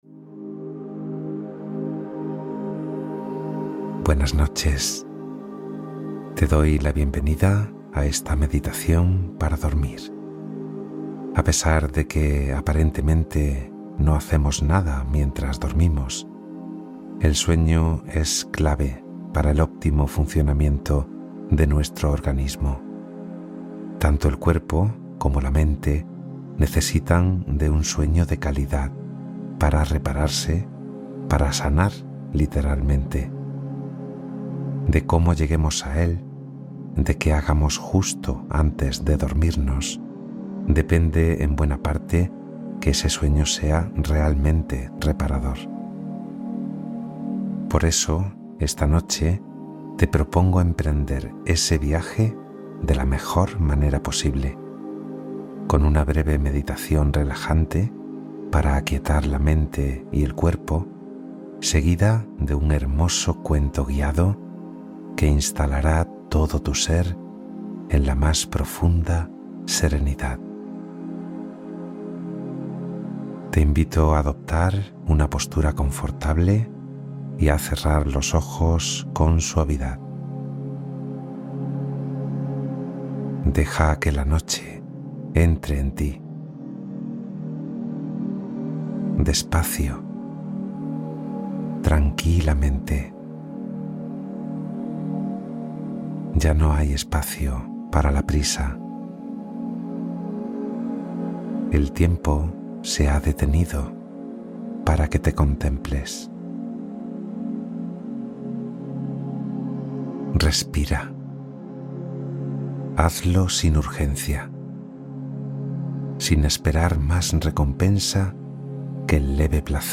Meditación de Descarga Mental para Soltar la Preocupación Nocturna